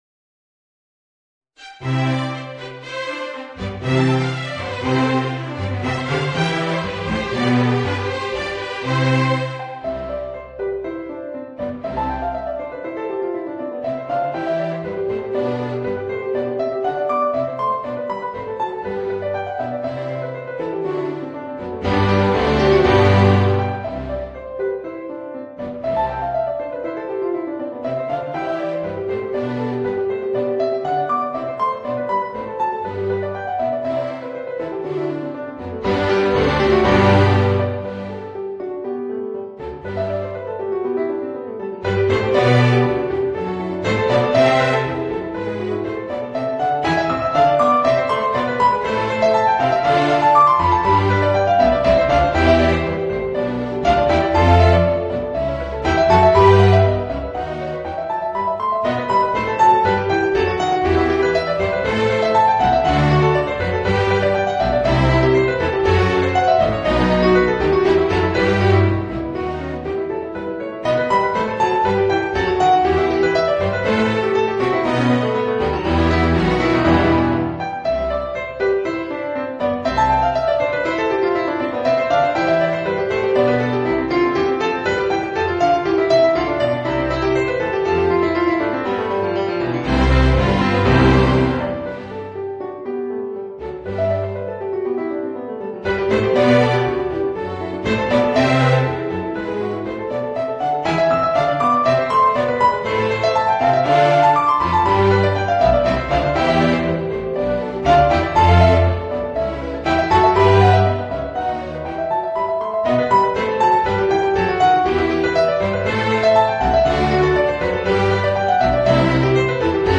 Voicing: Piano and String Orchestra